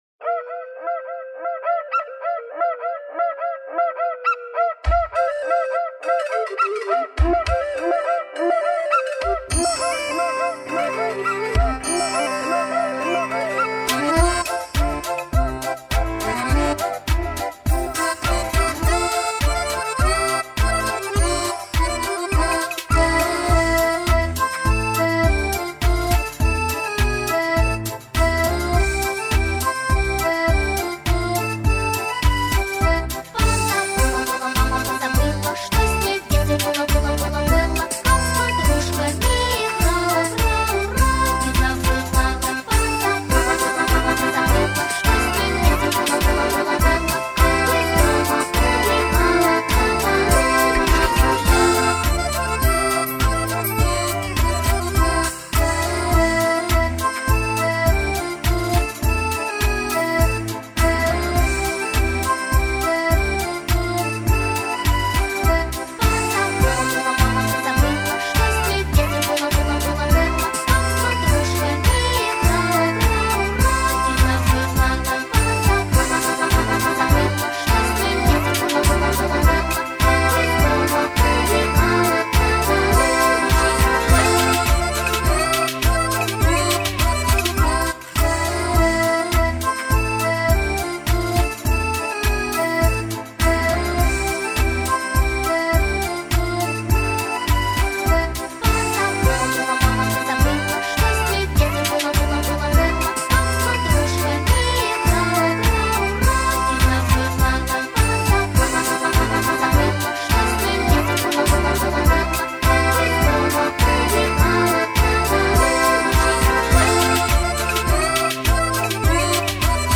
31 Мар 2015 Минусовка.